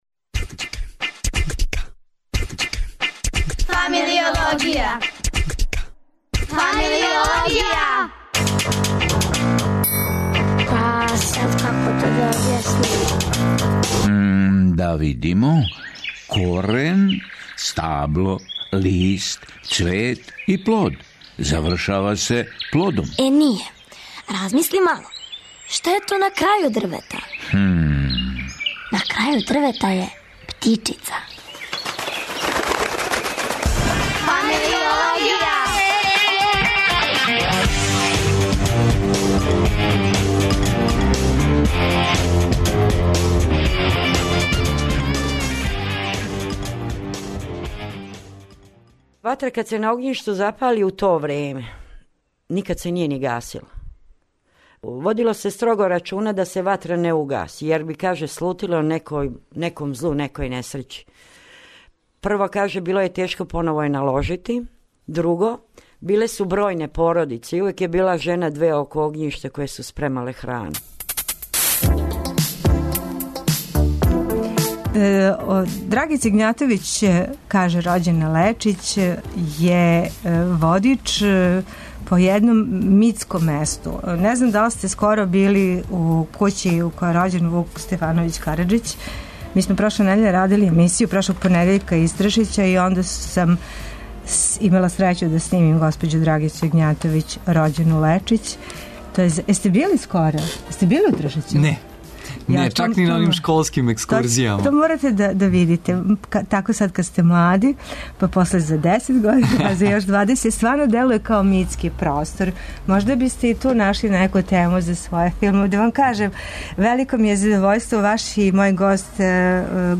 гост у другом издању серијала посвећеног вршњачком насиљу...